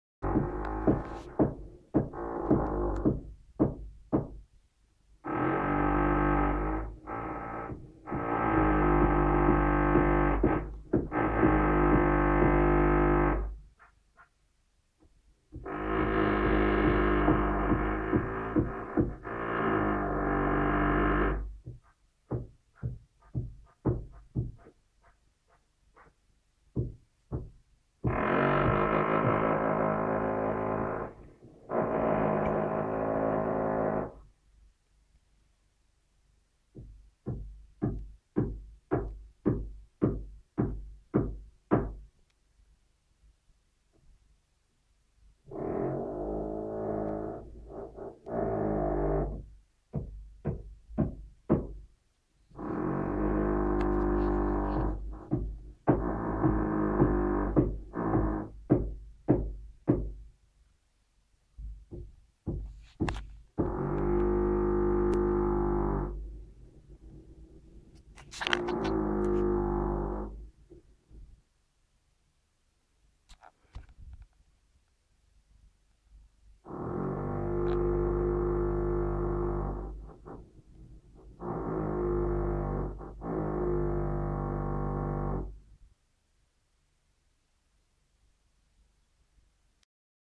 Звуки дрели, перфоратора
Раздражающий звук строительного инструмента от соседей